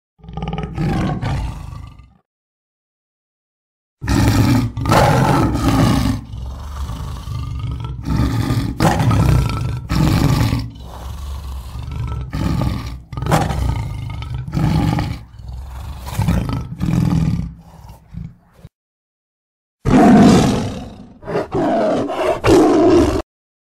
Tiếng Hổ kêu MP3 (Tiếng gầm hung dữ)